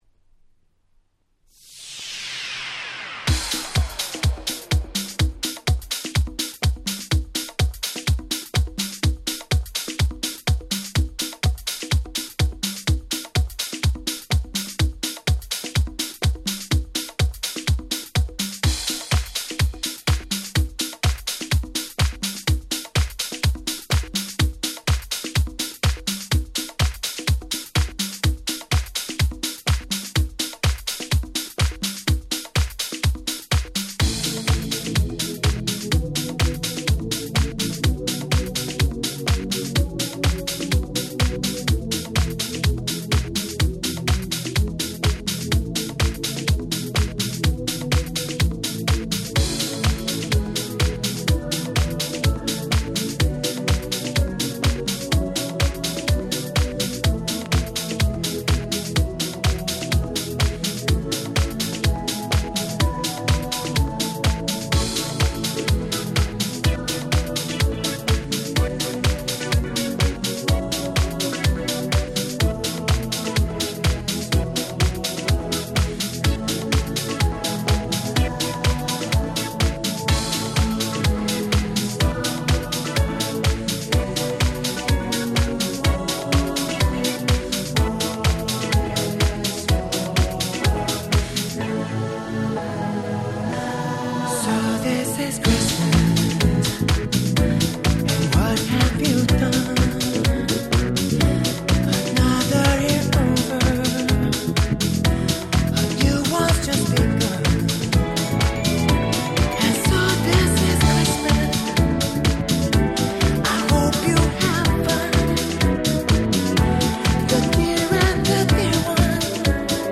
07' Nice Japanese House !!